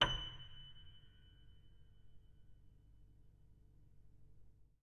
sampler example using salamander grand piano
Fs7.ogg